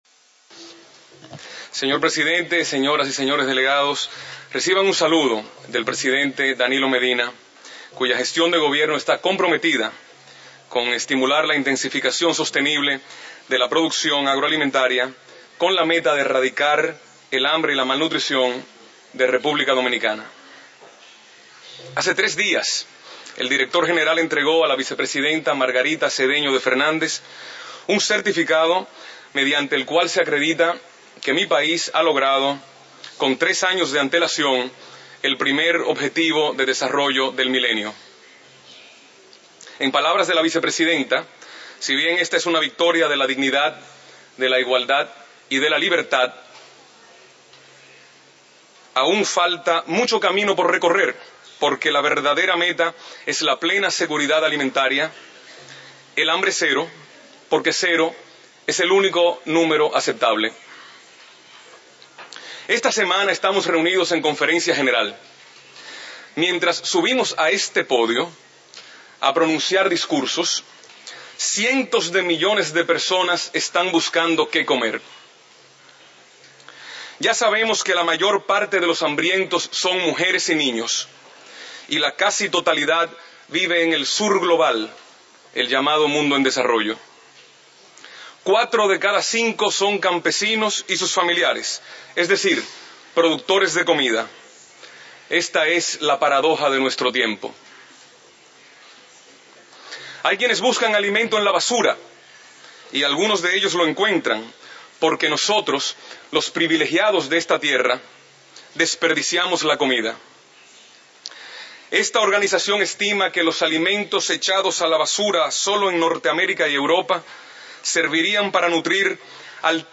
FAO Conference
Statements by Heads of Delegations under Item 9:
His Excellency Mario Arvelo Caamaño Ambassador of the Dominican Republic to FAO